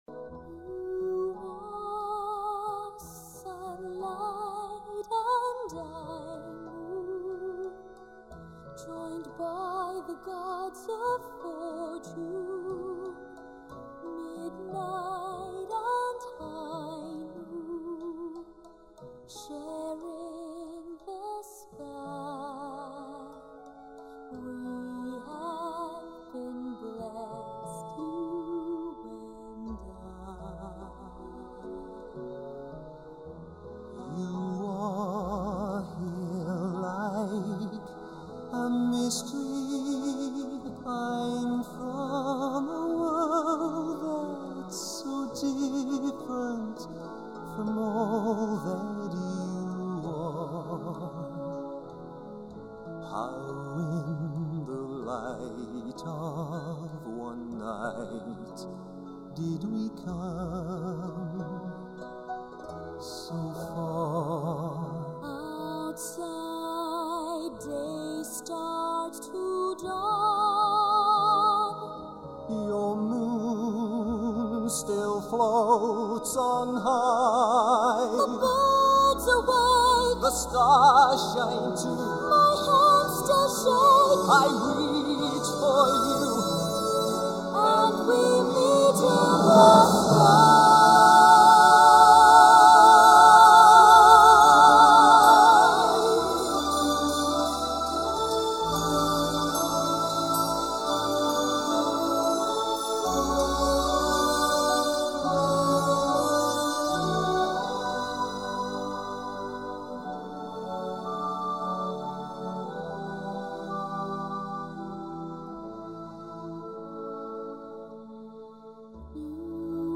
音樂類型：音樂劇